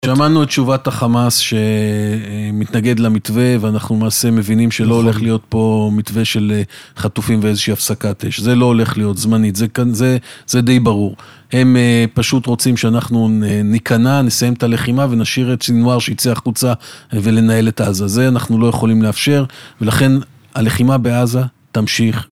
השר קיש בריאיון בתוכנית "הנבחרים" ברדיוס 100FM